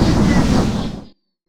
stormimpact03.wav